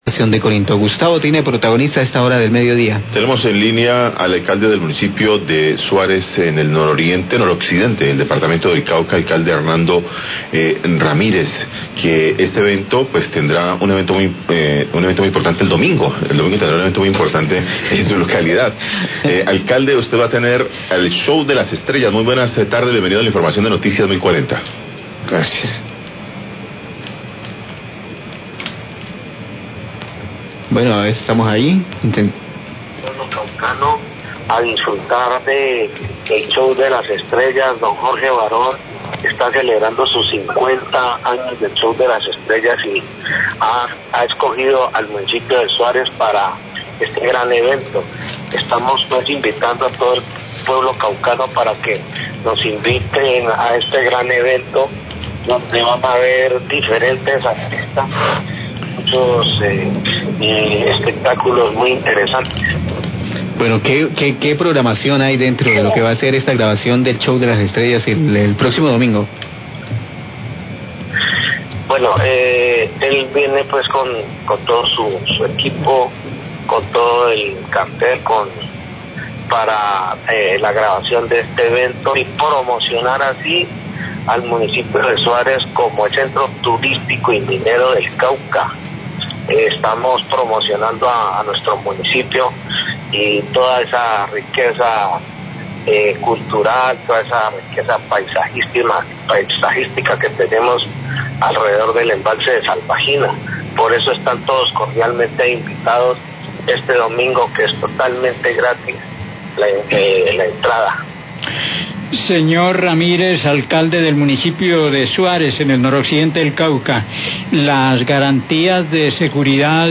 Radio
Declaraciones del Alcalde de Suárez, Hernando Ramirez.